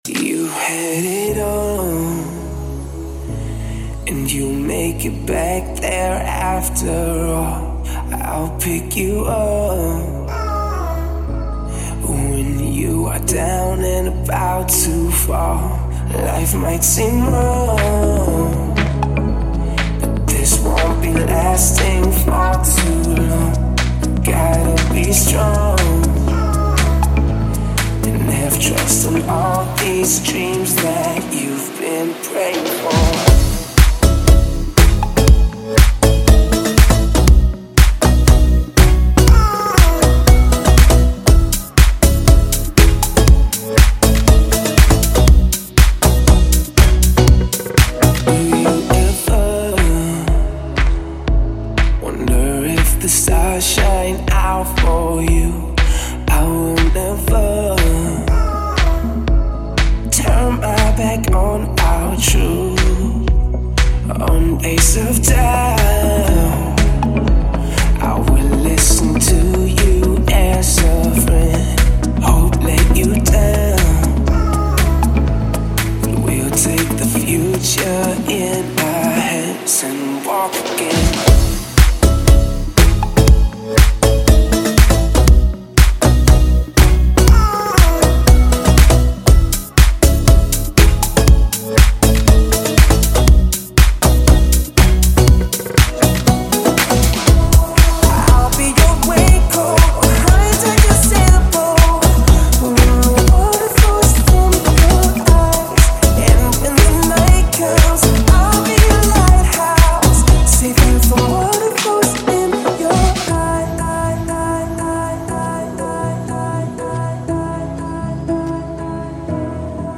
Релаксирует_и_заряжает_позитивом